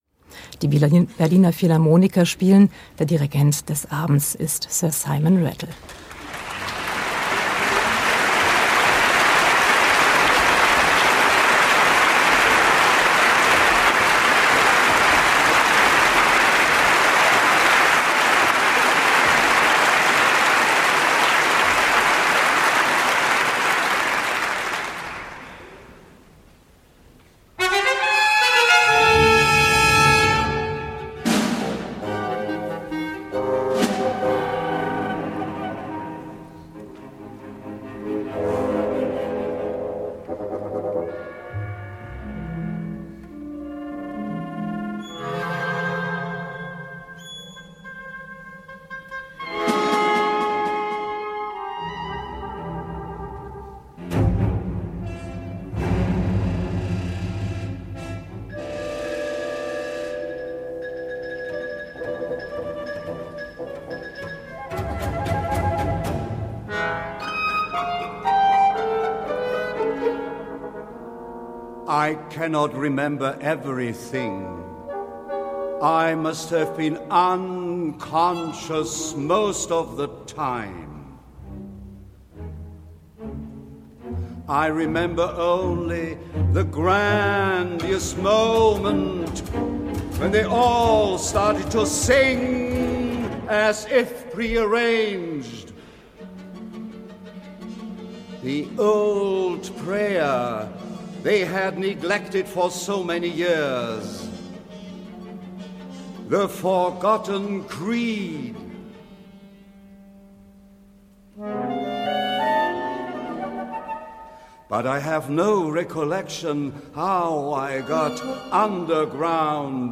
So as a reminder, not of the farewell concert, but of a typical Rattle/BPO collaboration, here is a concert recorded by RBB in Berlin on October 30, 2010, featuring music of Arnold Schoenberg and Gustav Mahler.
Kate Royal, Soprano
Mezzosoprano
Hanns Zischler, speaker in Survivor Of Warsaw. Certainly not an easy care-free concert – but a committed one with powerful connotations.